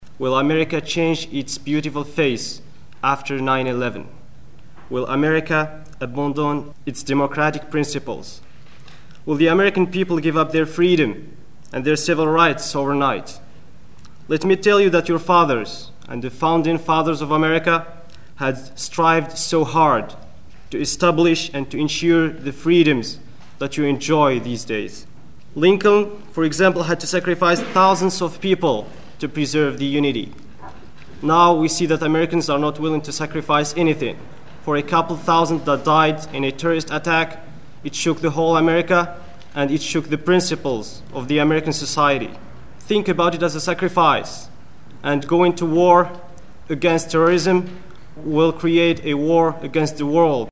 A man from the Muslim Student Union at Laney College speaks at the antiwar organizing meeting in Oakland 9/21/01 (0:35)